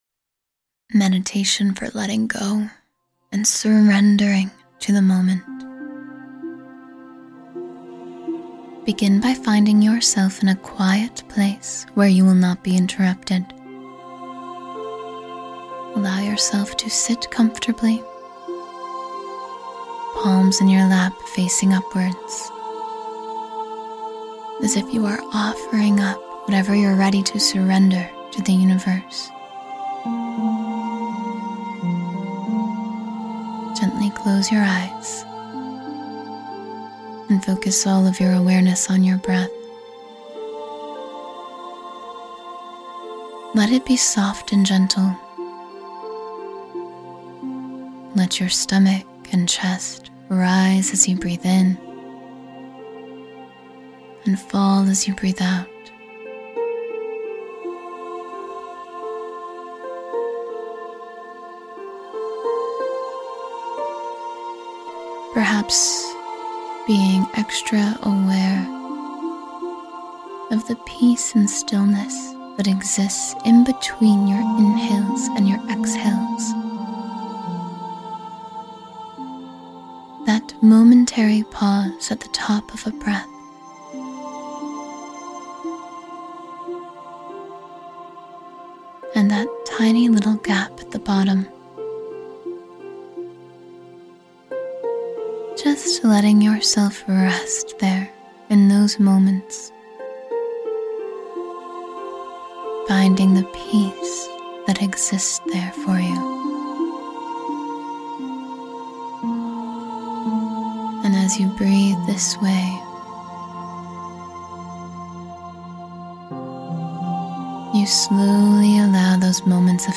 Let Go and Surrender — Find Your Inner Peace with This Guided Meditation